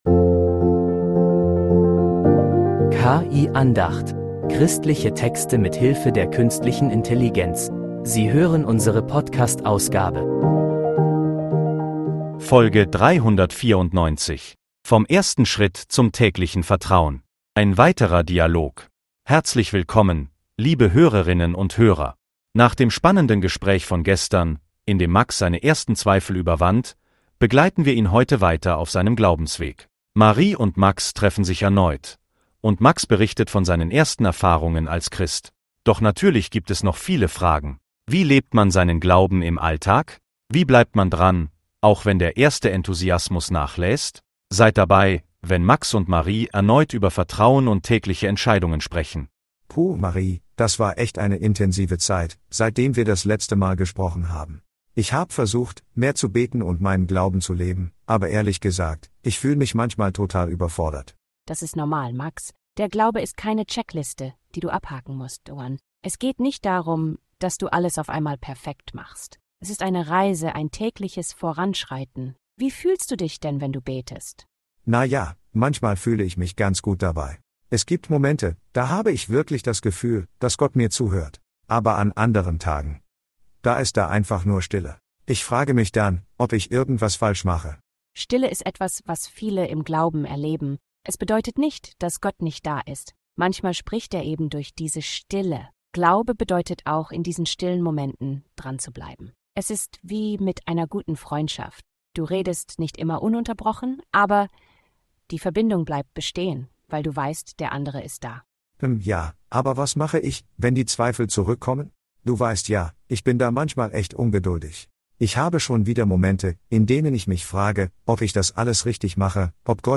Ein weiterer Dialog